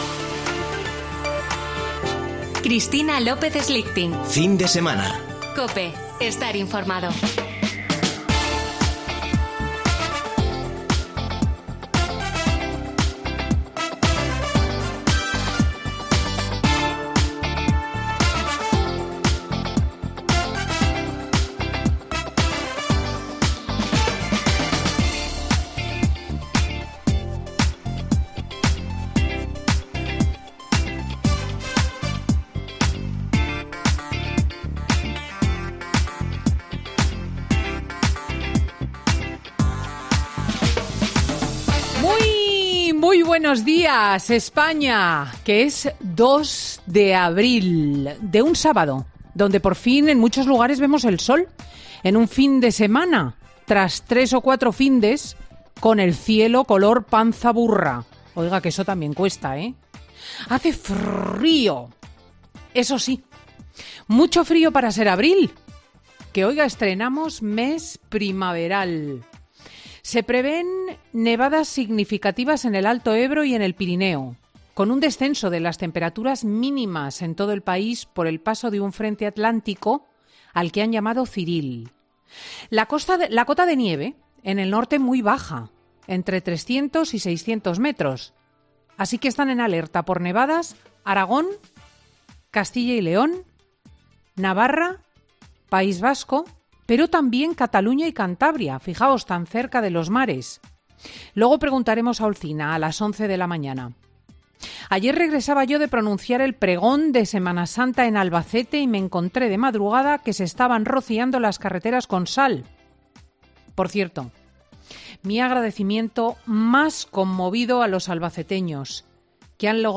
El congreso de los populares en Sevilla, o el cerco judicial contra Mónica Oltra, entre los temas del monólogo de Cristina López Schlichting de...